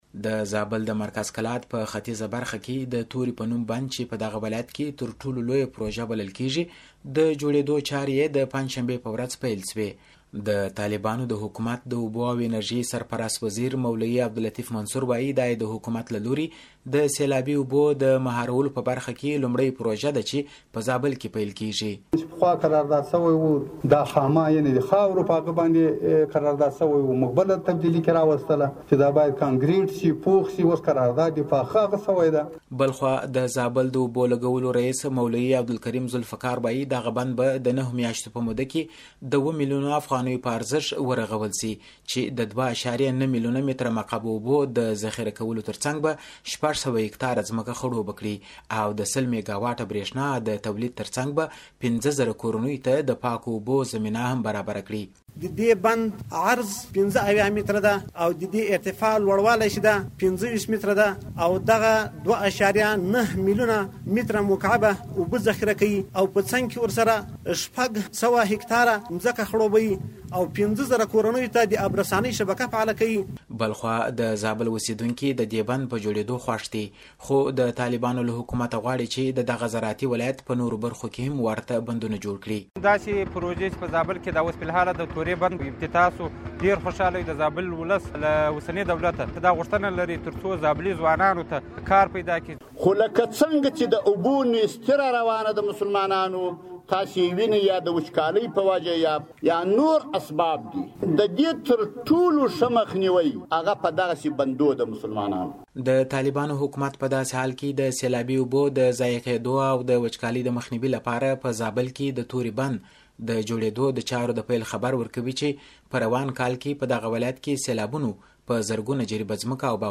د زابل راپور